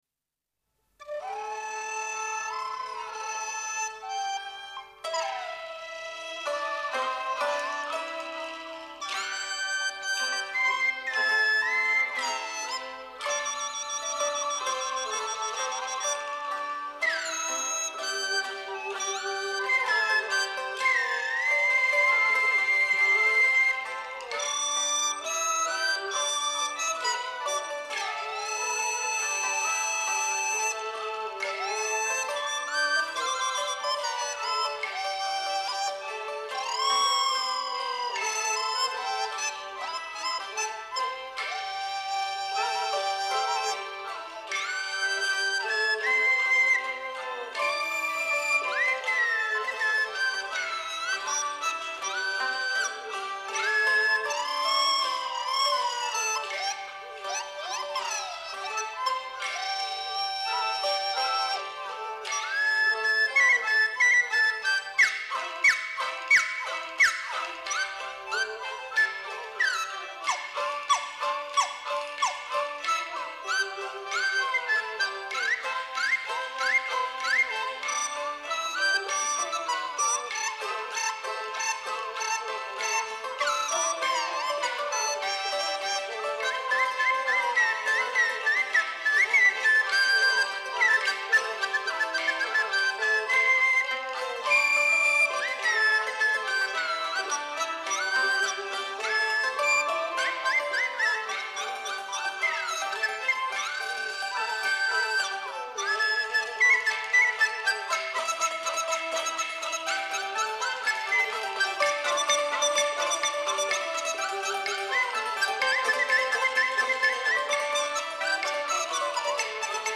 华北地区的民间器乐曲牌
这些乐曲中，有相当一部分是50年代或60录制的，由于年代较远，音响效果不甚理想，然而，历史性录音的珍贵价值弥足珍贵。